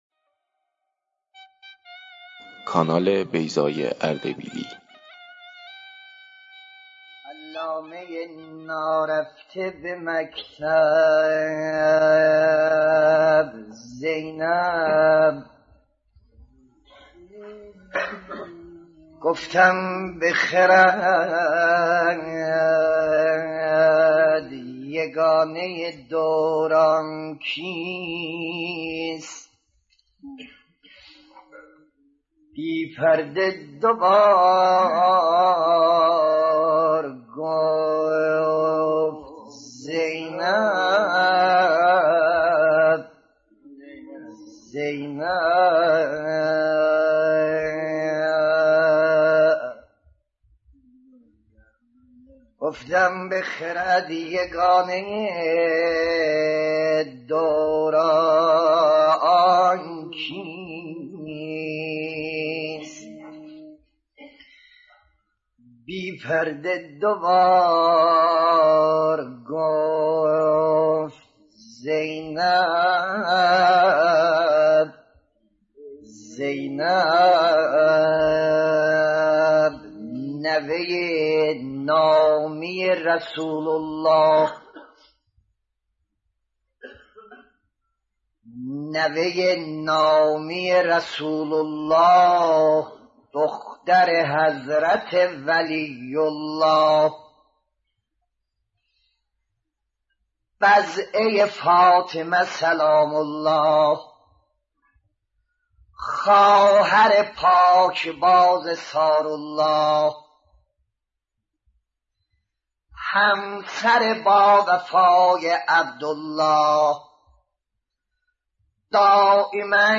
مداحی ترکی | ماجرای تولد و عروسی حضرت زینب سلام الله علیها
مداحی آذری